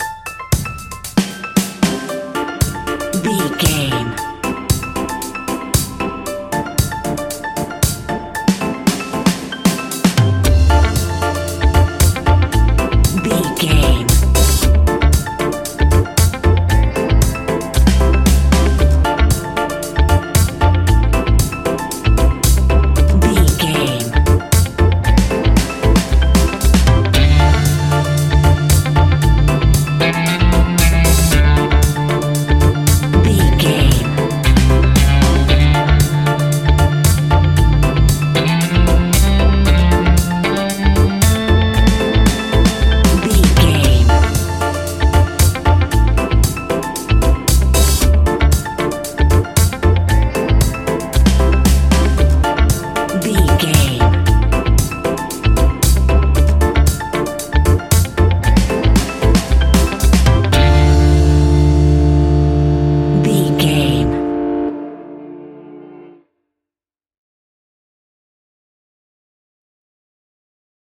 Aeolian/Minor
D
instrumentals
laid back
chilled
off beat
drums
skank guitar
hammond organ
percussion
horns